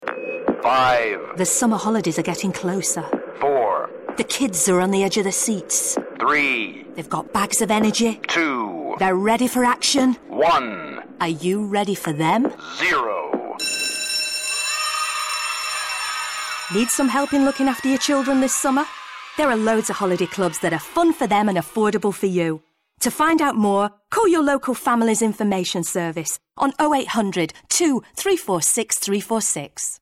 40/50's Northern,
Friendly/Sassy/Warm